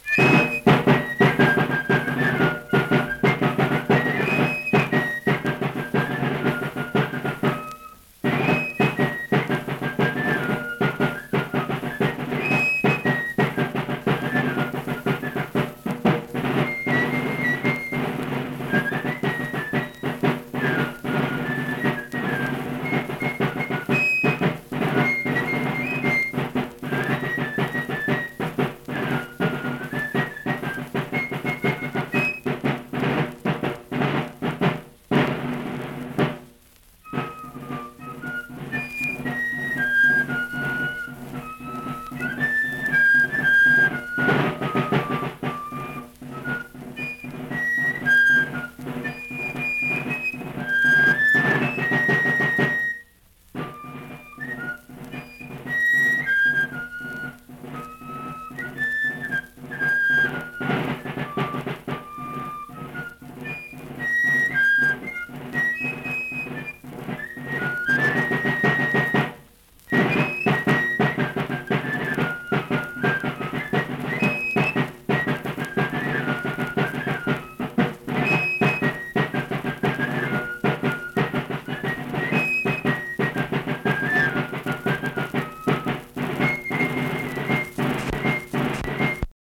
Unaccompanied fife and drum music
Verse-refrain 2(3). Performed in Hundred, Wetzel County, WV.
Instrumental Music
Fife, Drum